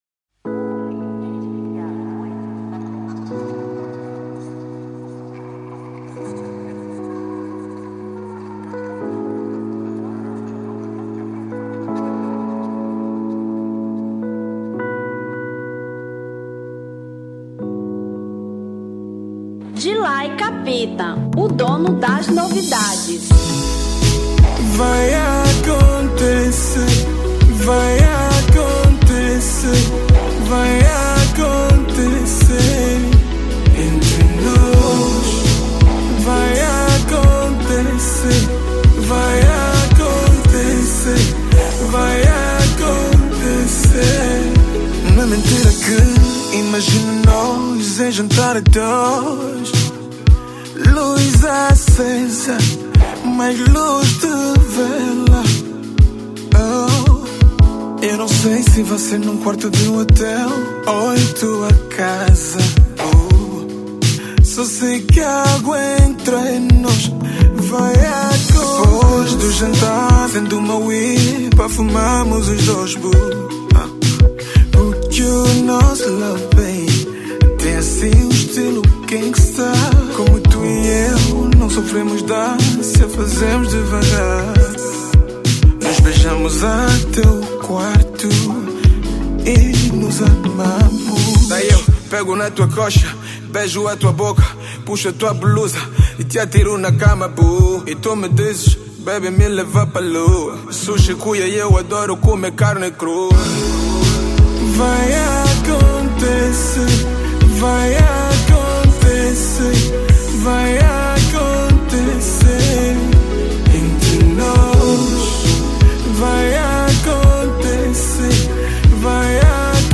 Zouk 2017